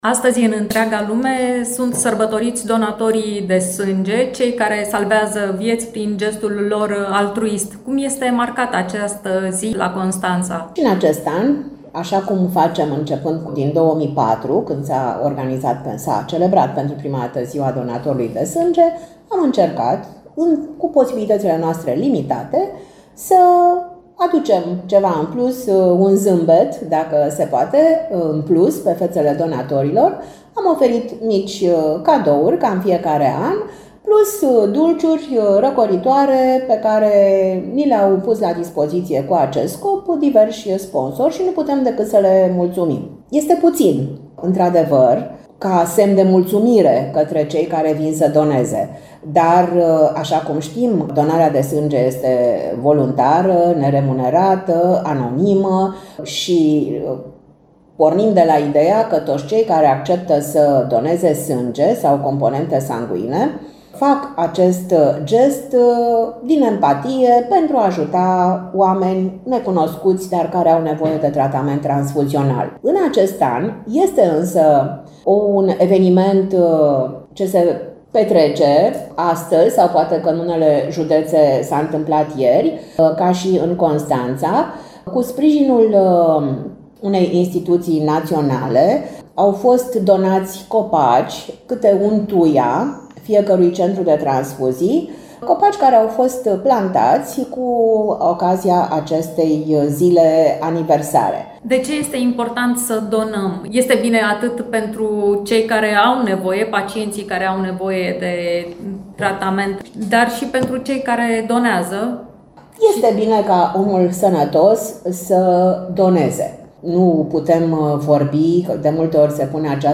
Despre satisfacțiile pe care ți le aduce donarea de sânge stăm de vorbă cu două donatoare fidele, mamă și fiică